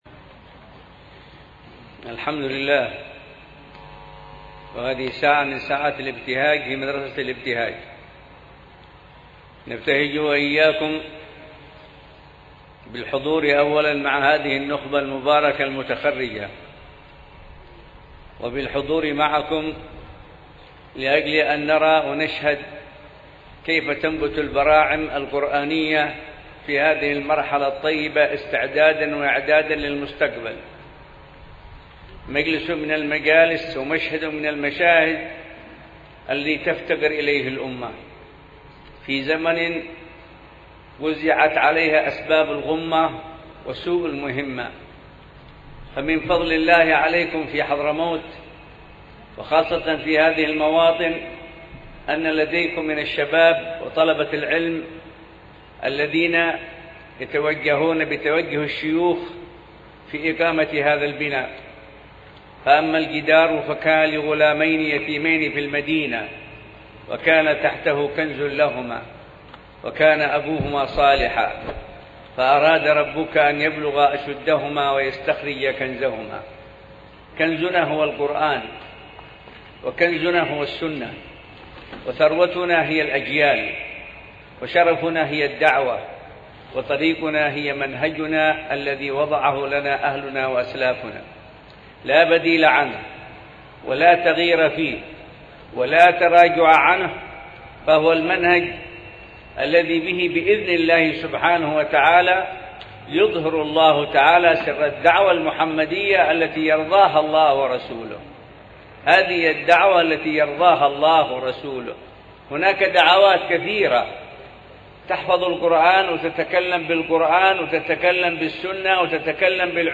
في مجلس ختم القرآن الكريم لتكريم وتشريف حملة كتاب الله عز وجل بمدرسة التبيان بمدينة سيئون بحضرموت